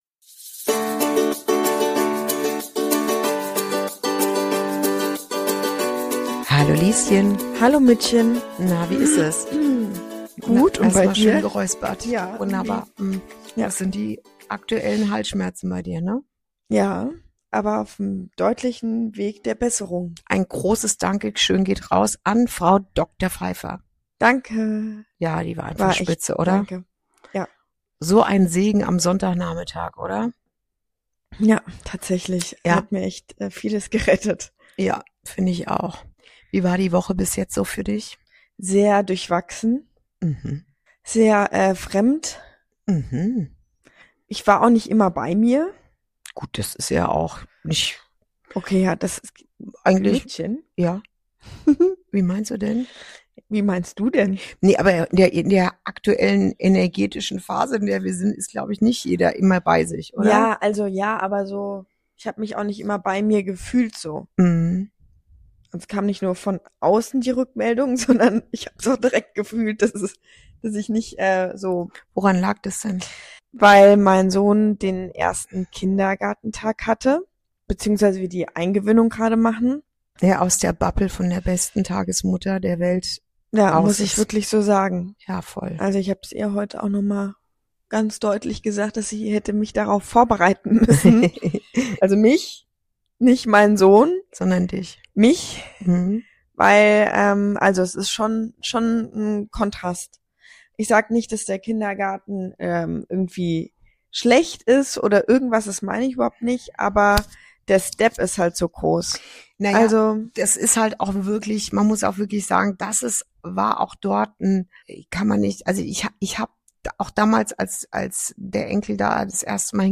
Folge 16: Übergänge, Ohrwunder & Kindergartenmut ~ Inside Out - Ein Gespräch zwischen Mutter und Tochter Podcast
Ein Gespräch über Menschlichkeit, Veränderung und das, was bleibt – wenn alles sich verändert.